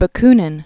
(bə-knĭn, -nyĭn), Mikhail Aleksandrovich 1814-1876.